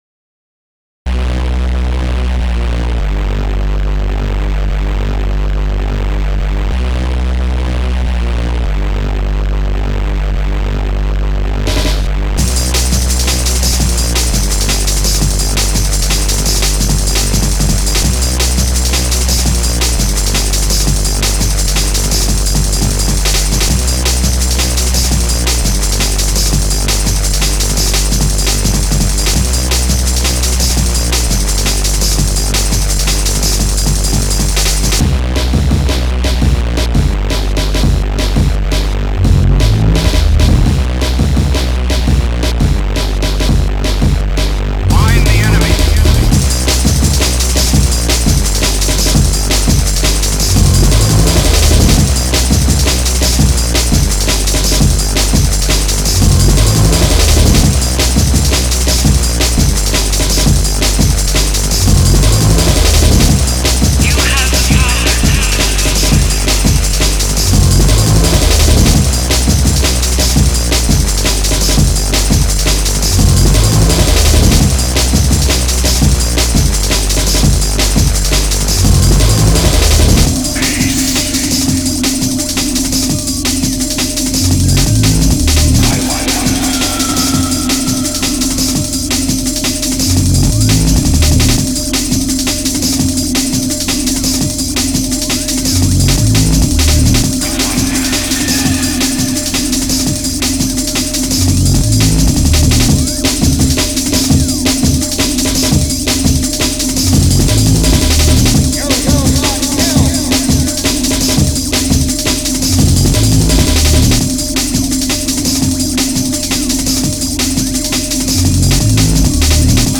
Hard Drum'n bass